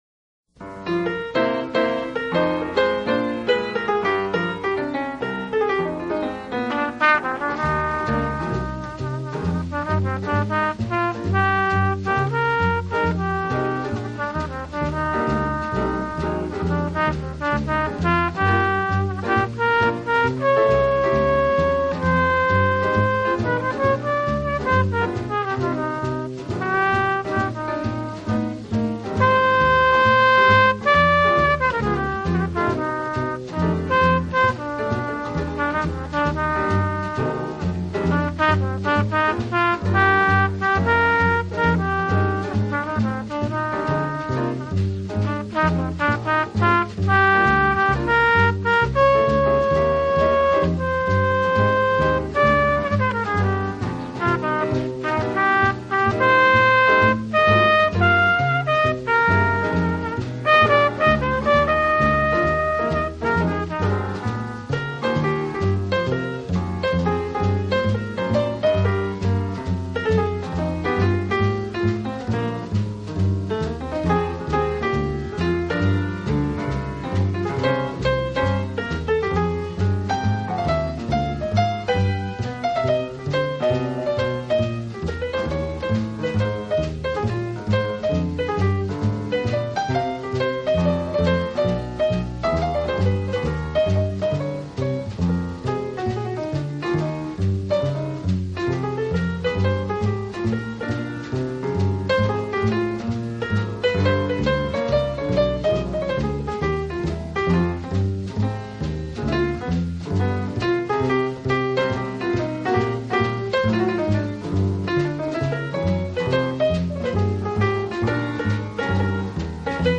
【爵士小号】
音乐类型：Jazz